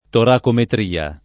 [ torakometr & a ]